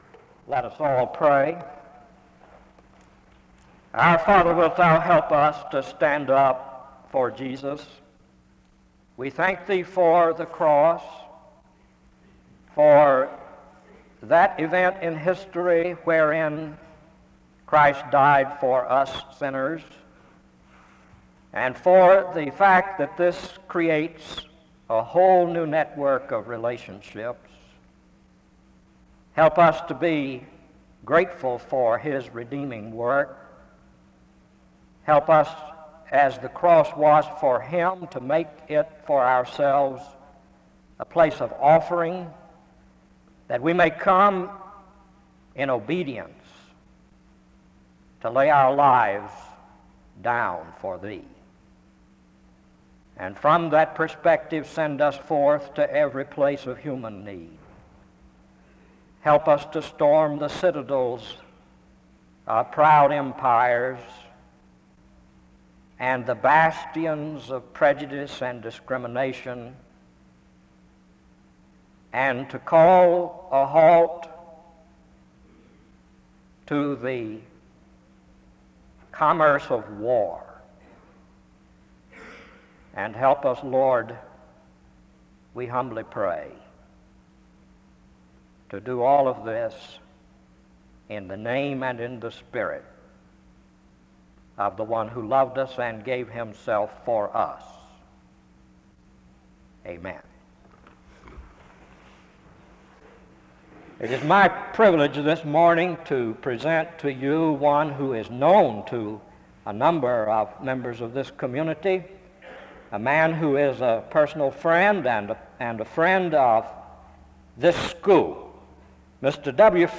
The service closes in prayer (22:41-23:05).
SEBTS Chapel and Special Event Recordings - 1970s